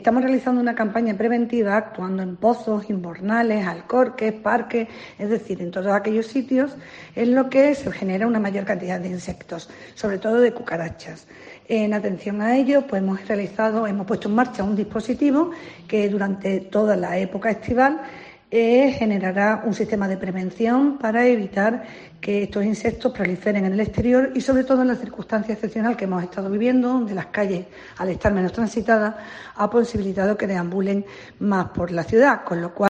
Esther Cumbrera, viceportavoz y concejala de Hábitat Urbano e Infraestructuras